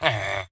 mob / villager / no1.ogg